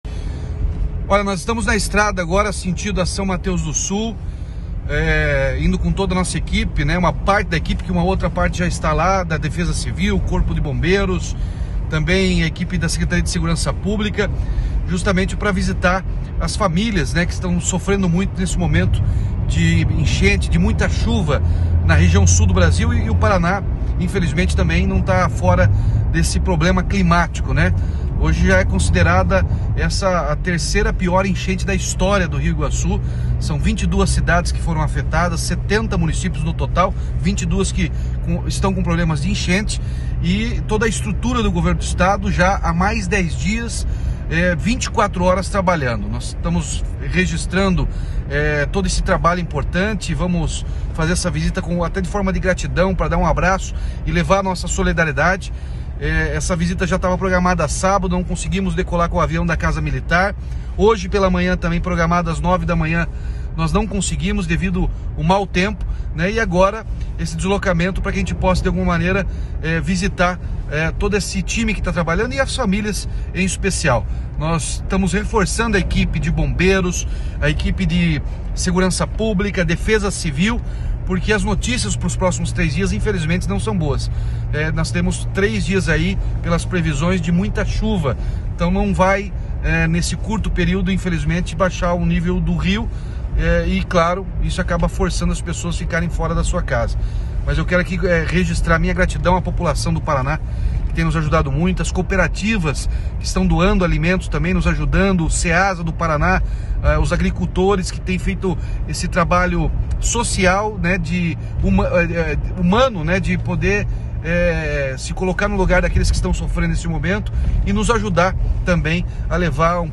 Sonora do governador Ratinho Junior sobre a visita a São Mateus do Sul, um dos municípios mais afetados pelas chuvas dos últimos dias